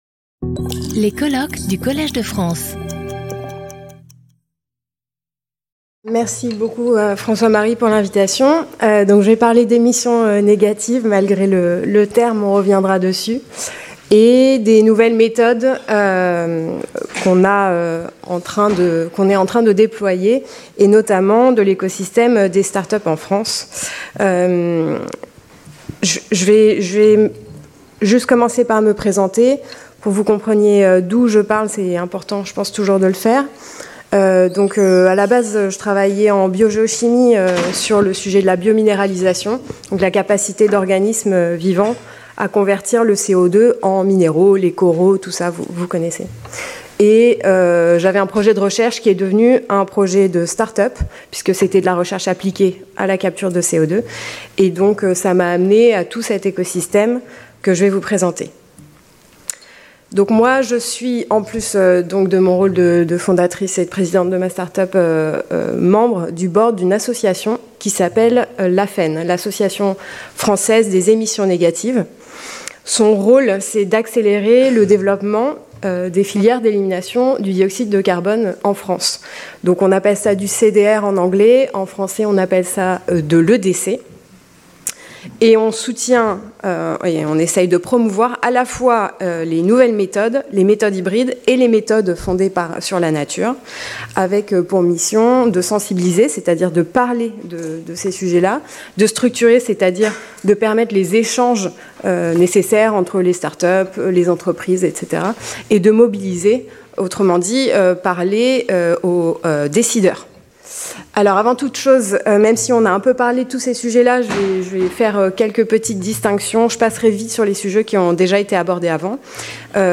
This presentation will focus on the French ecosystem of start-ups developing these solutions. It will analyze the technological concepts involved, their stage of development, the business models envisaged, as well as financing requirements in a context where regulatory frameworks, notably around the carbon market and certification mechanisms, are still under construction.